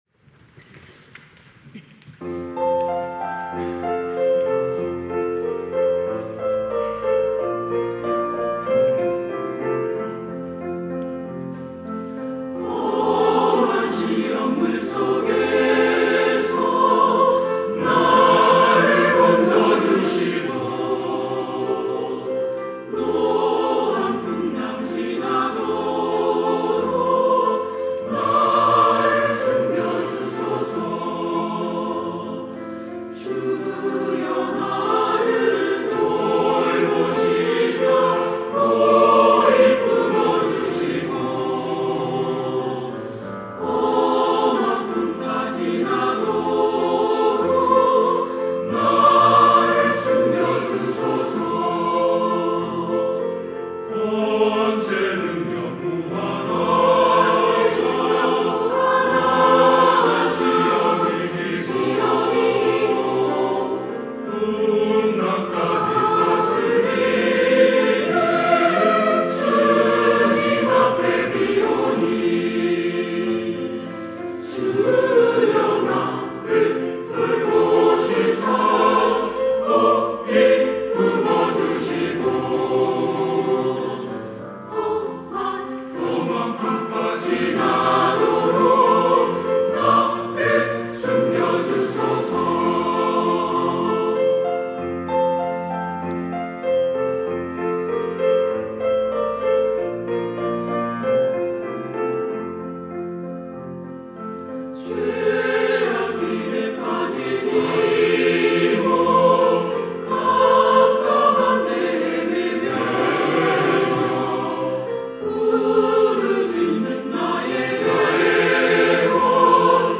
시온(주일1부) - 험한 시험 물속에서
찬양대